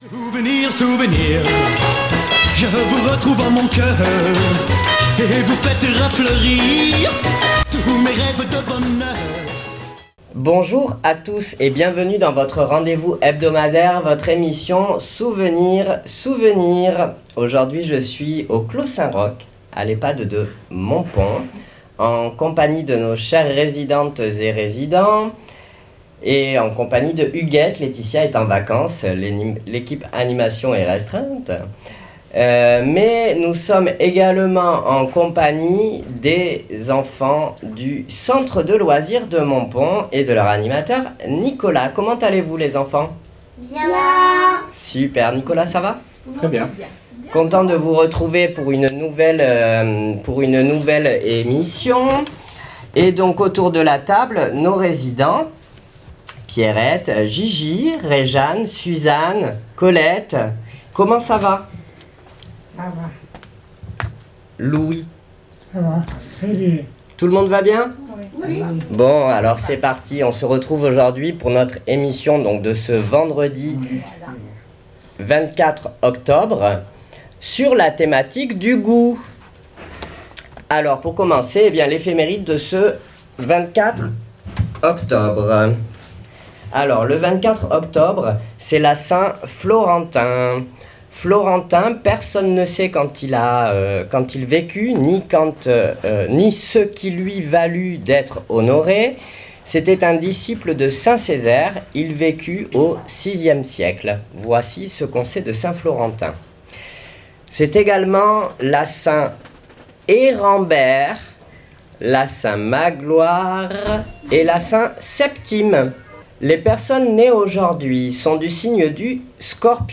Souvenirs Souvenirs 24.10.25 à l'Ehpad de Montpon " Le goût "